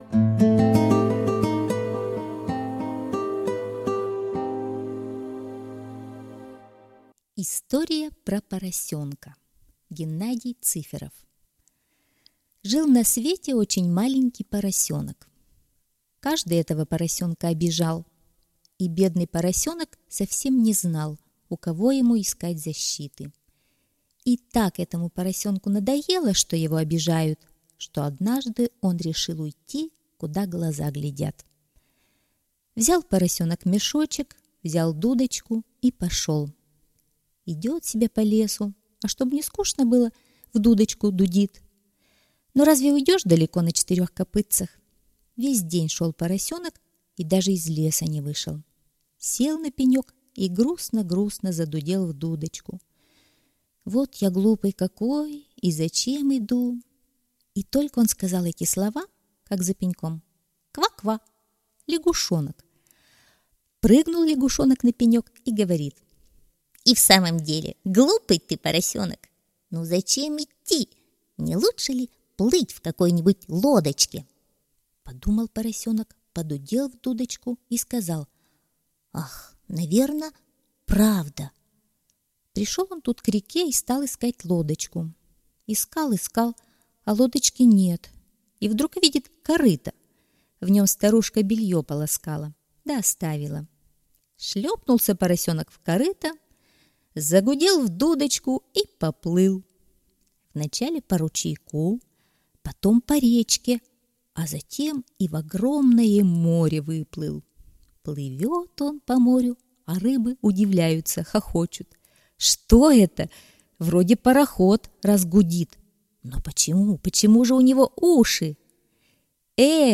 История про поросёнка - аудиосказка Цыферова Г.М. История про маленького поросенка, которого все обижали.